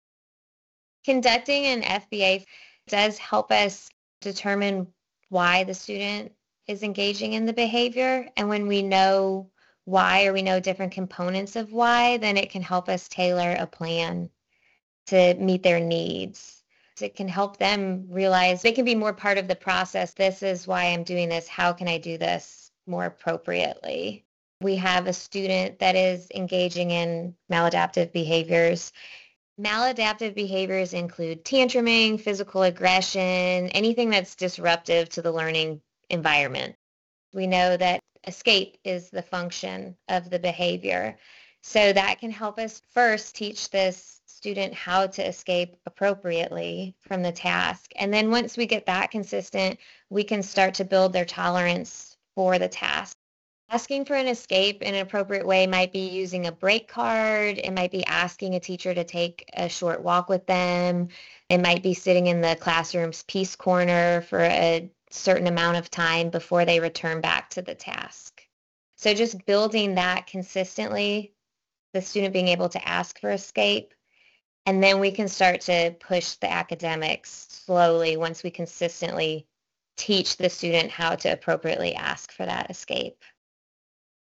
Behavior Analyst; Former Teacher